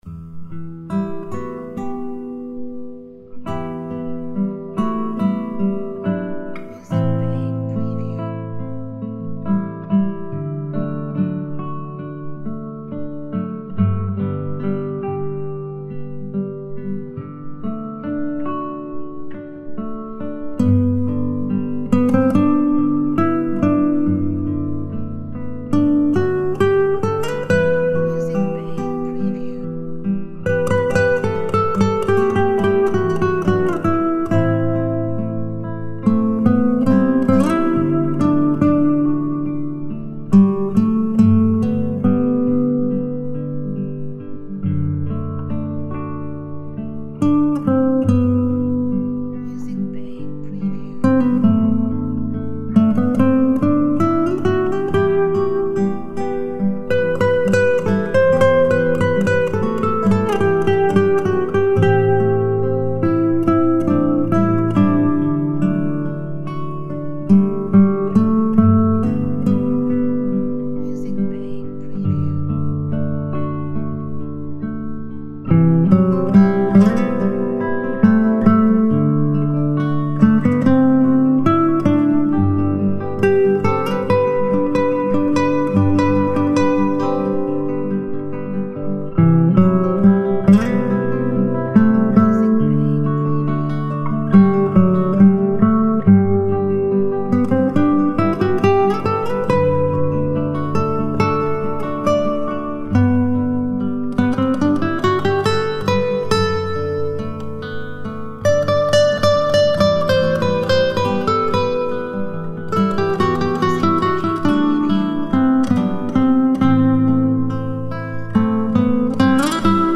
Music instrumentals for film.